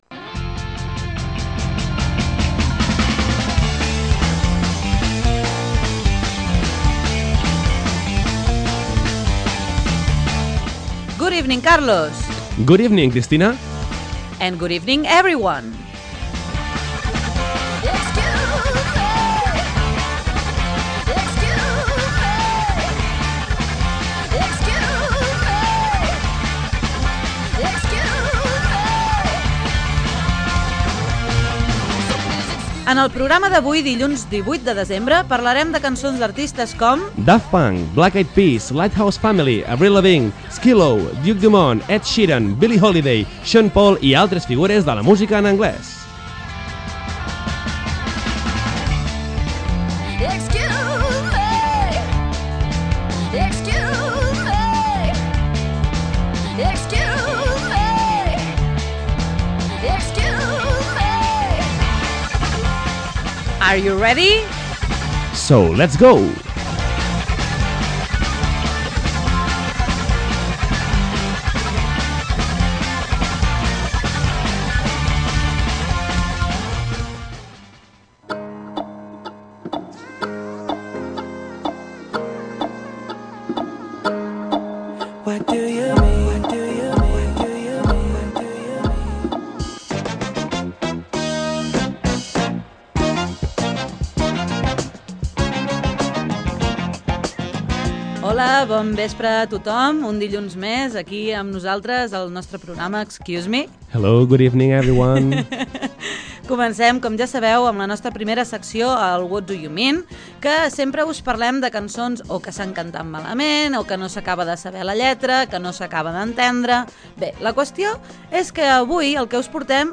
En aquest programa destaquem cançons on els artistes canten amb la veu modificada digitalment, cançons relacionades amb els desitjos, les frases amagades en dues cançons com cada setmana i les respostes a les peticions dels oients.